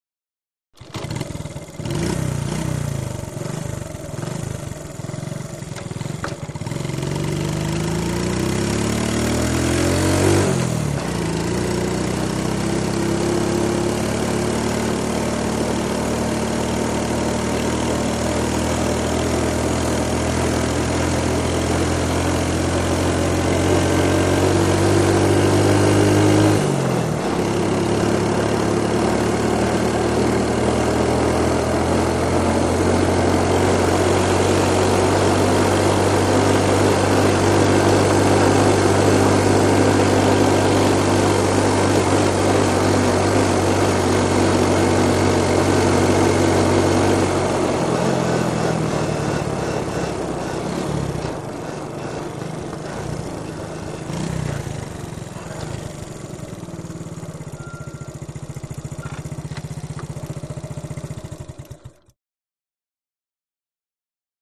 Motorcycle; Constant; Two Stroke Trike Start Up And Away To Constant Riding On, Finally Slows, Stops, And Switch Off With Light Engine Pings.